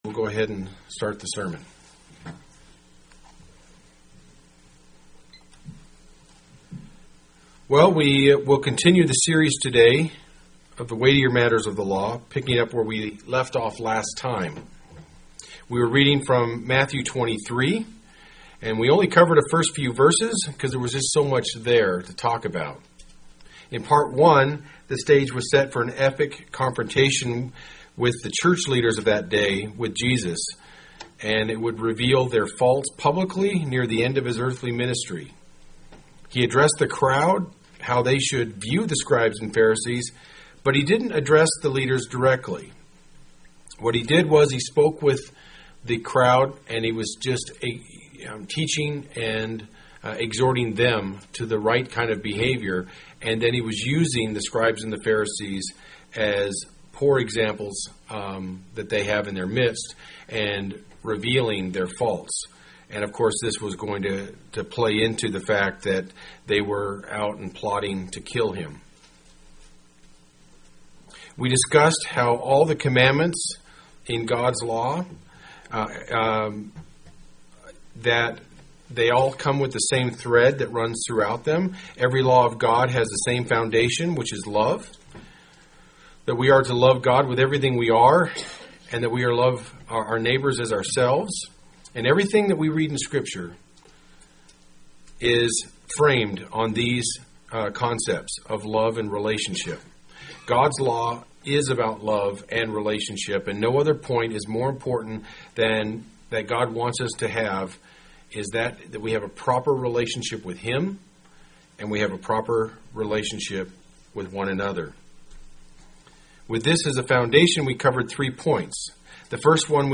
UCG Sermon Notes We continue the series today with the Weightier matters of the Law picking up where we left off last time.